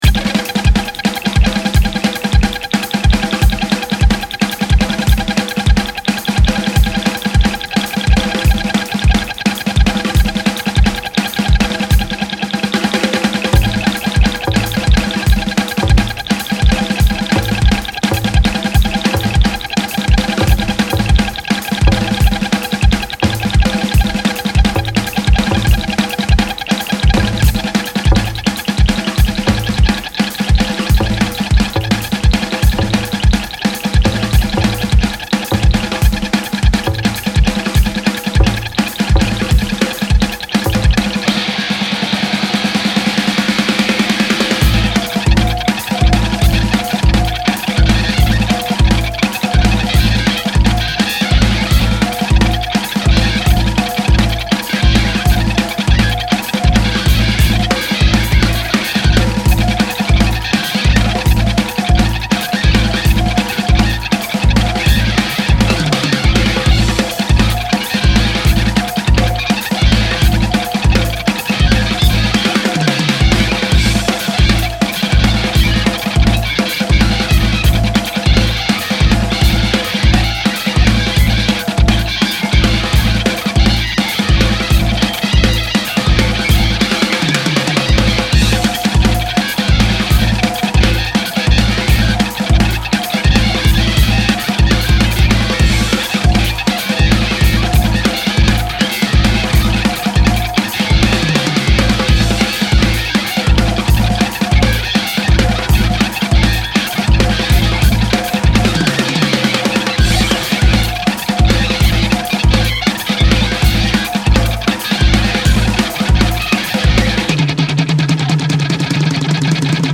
Le bordel dure 5 minutes et, franchement, on mérite bien ça.